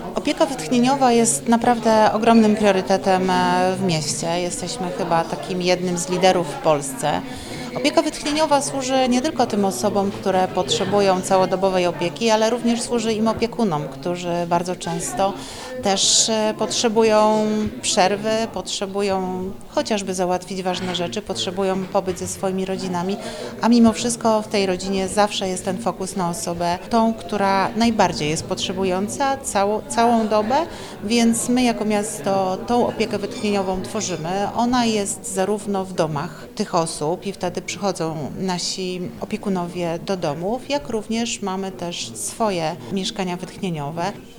Myślimy o naszych mieszkańcach, myślimy o ludziach starszych, myślimy o ludziach z niepełnosprawnościami, myślimy o ludziach przewlekle chorych, bo te miejsca są właśnie dla takich osób, dla takich mieszkanek i mieszkańców Wrocławia – mówi wiceprezydent Wrocławia Renata Granowska.